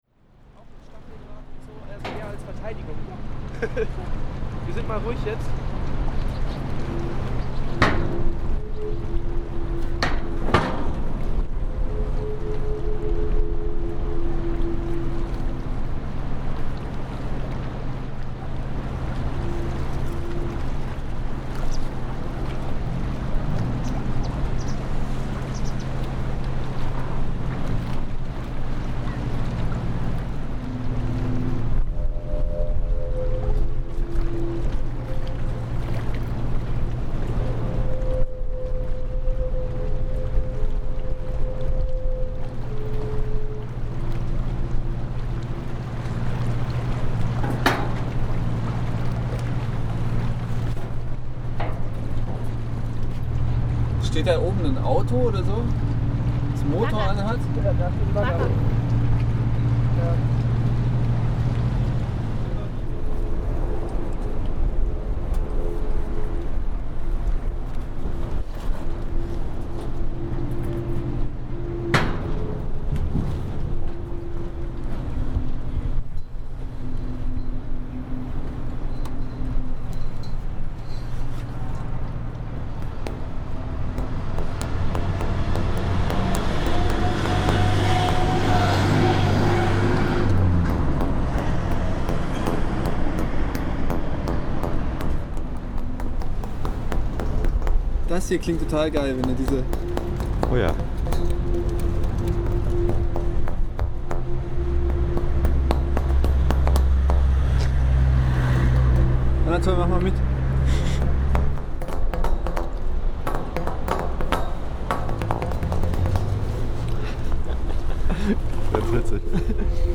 Arles_windharfen_solo.mp3